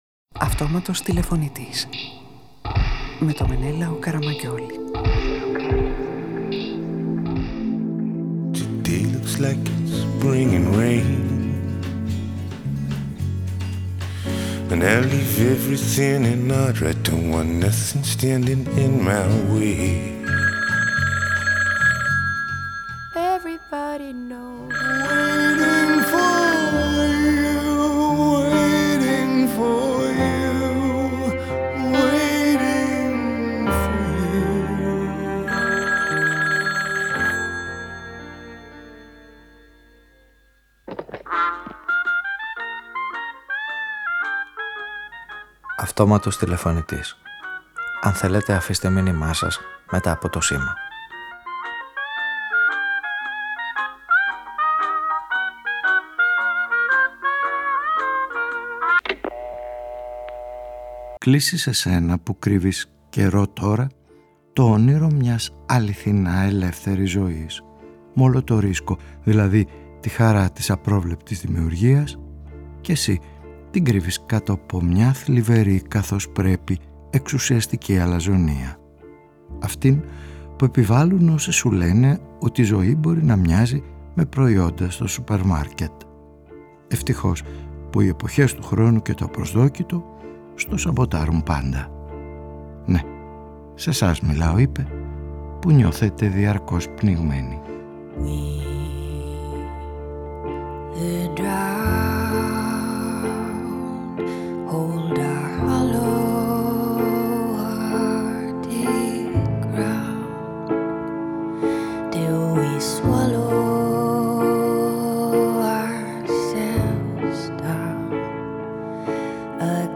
Ραδιοφωνικη Ταινια